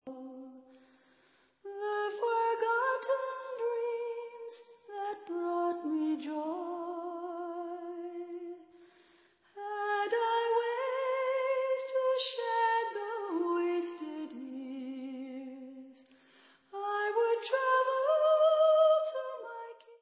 1996 studio album w.
Pop/Symphonic